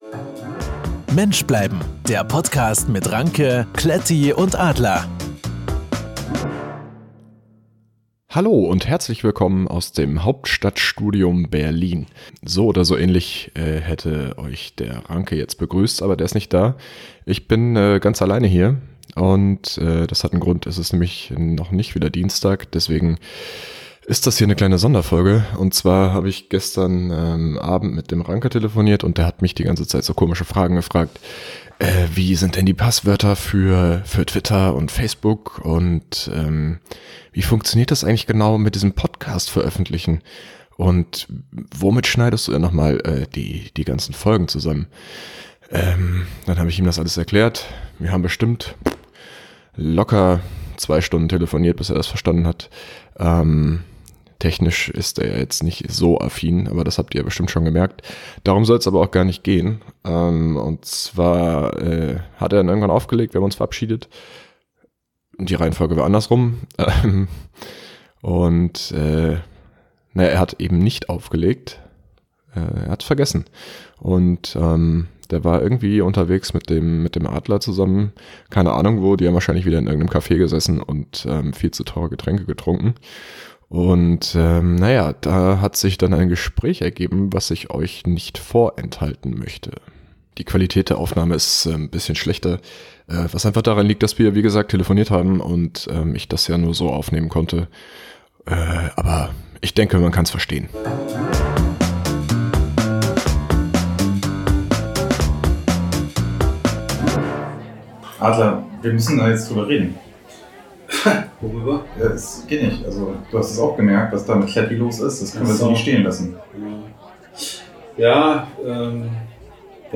Zwar ist die Qualität wegen der gegebenen Umstände nicht sonderlich gut, aber dieses Gespräch sollte unseren Hörern nicht vorenthalten werden.